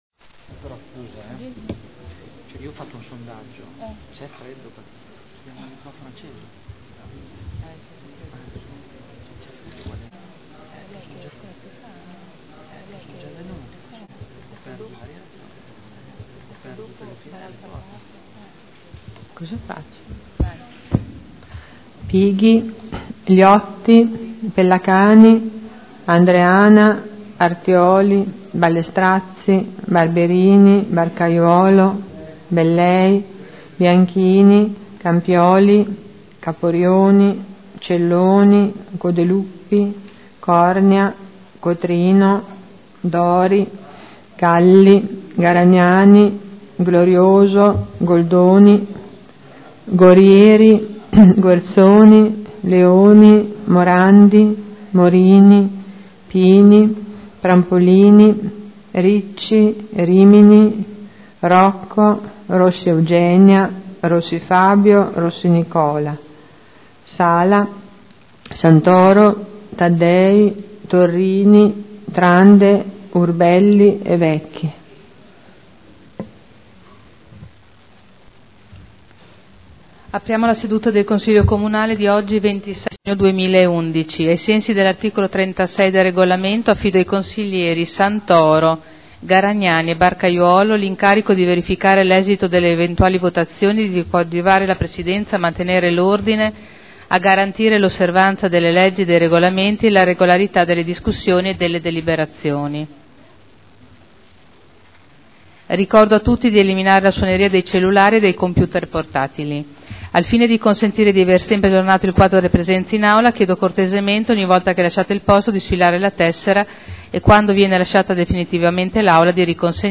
Vice segretario generale — Sito Audio Consiglio Comunale
Appello Apertura della seduta del Consiglio Comunale del 27/6/2011